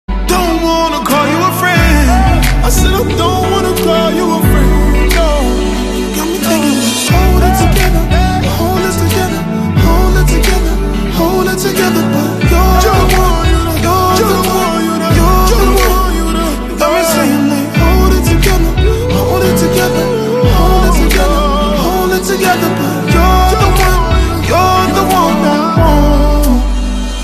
M4R铃声, MP3铃声, 欧美歌曲 96 首发日期：2018-05-14 14:33 星期一